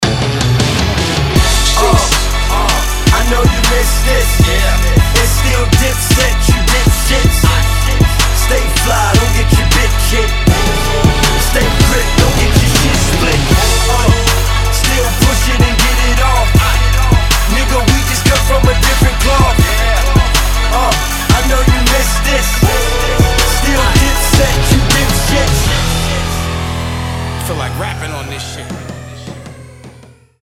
• Качество: 320, Stereo
Новый Хип Хоп сингл